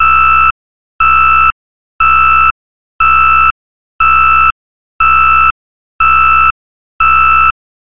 Vintage Warning Alarm